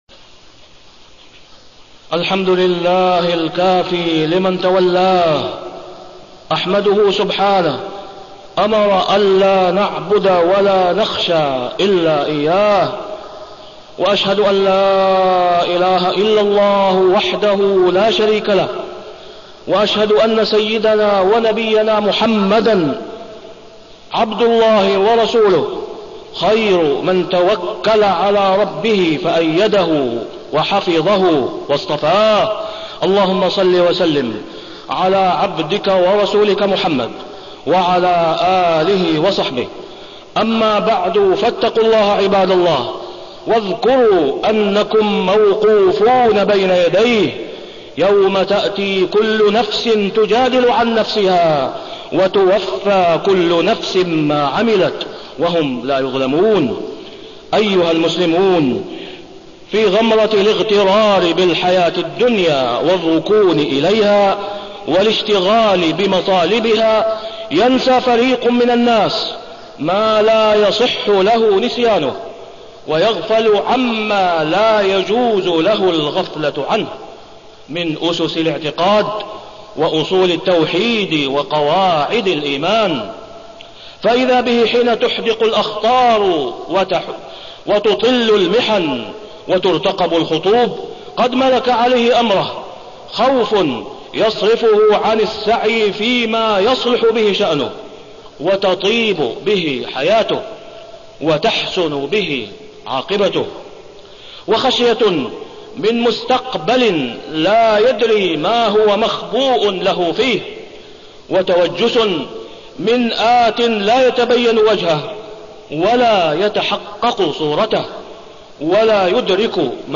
تاريخ النشر ١٣ جمادى الأولى ١٤٢٧ هـ المكان: المسجد الحرام الشيخ: فضيلة الشيخ د. أسامة بن عبدالله خياط فضيلة الشيخ د. أسامة بن عبدالله خياط الخشية من الله تعالى